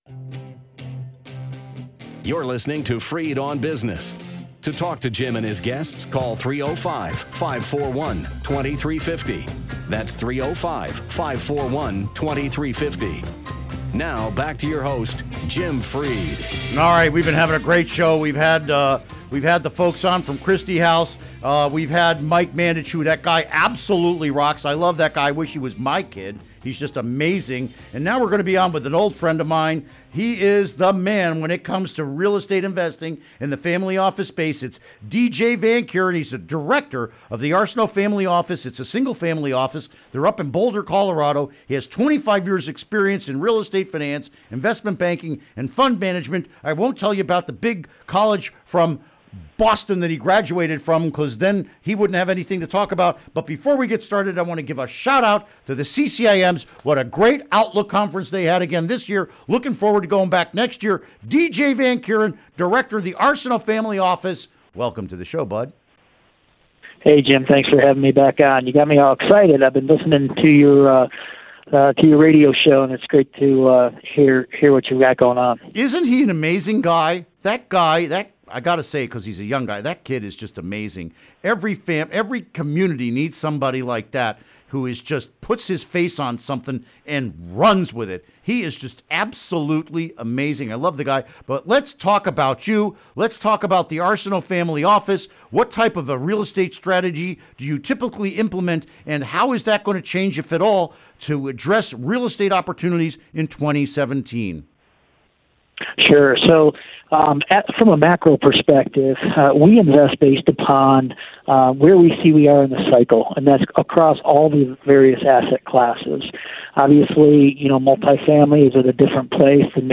We discuss his family office real estate strategies for 2017 and the assumptions about the market that drive the plan. Interview Segment Episode 403: 01-19-17 Download Now!